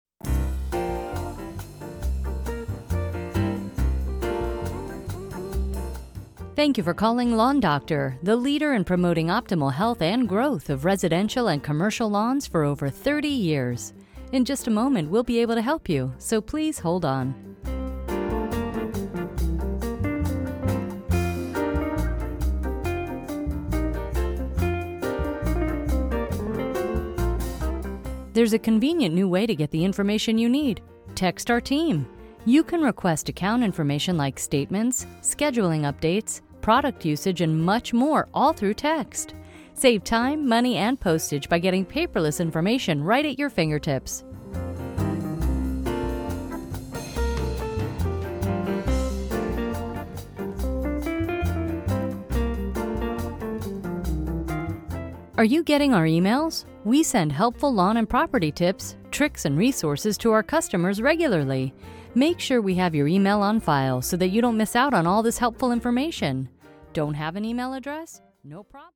You want to create the best “user experience” that will combine interesting, helpful information on hold recorded by a professional voice over with the best hold music…nothing that your caller will sing along with…just pleasant background music.
Lawn-Doctor-Boston-Sample.mp3